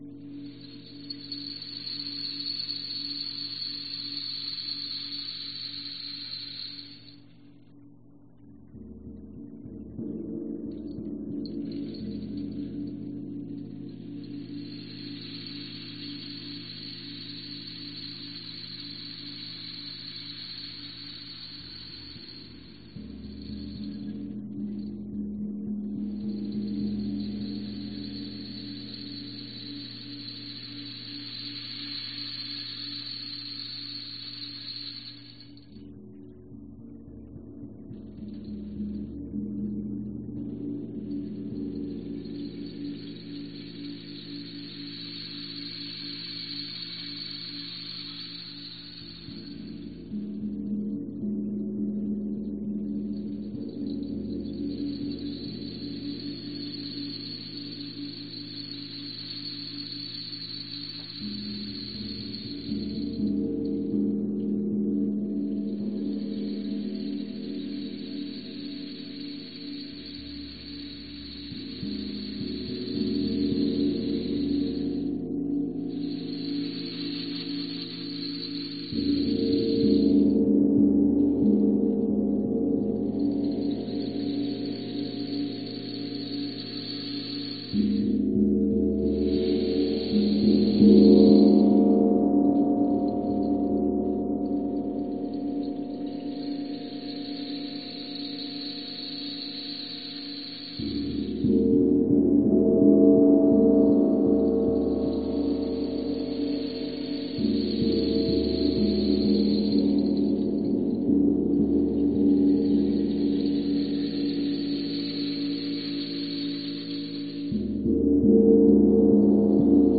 Tag: 洛菲 自然 雷鸣 闪电 锣鼓